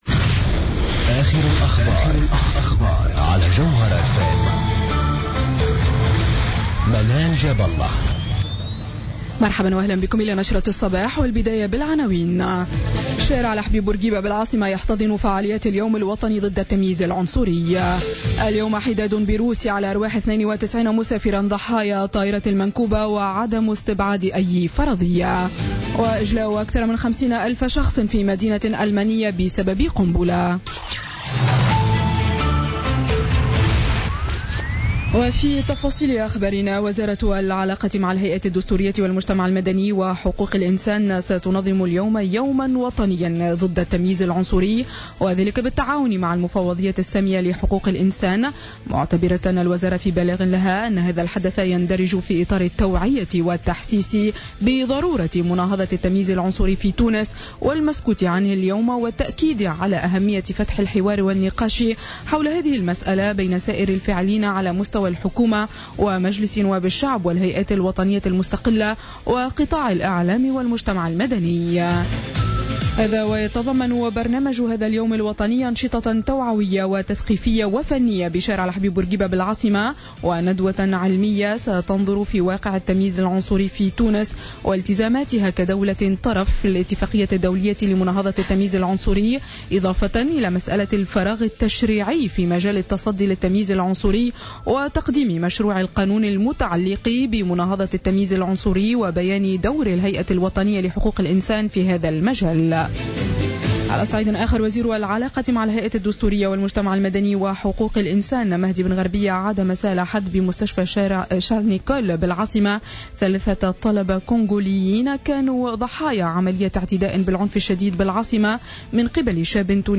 نشرة أخبار السابعة صباحا ليوم الإثنين 26 ديسمبر 2016